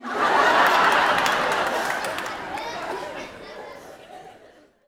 Increased volume of laugh tracks again
Audience Laughing-09.wav